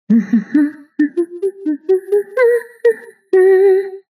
voice line - humming